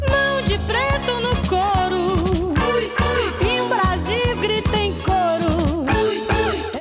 Знающие люди, хелп(Босса нова)
Перкуссия выпирает и динамически убогая...